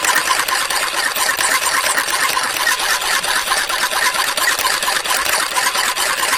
fan.ogg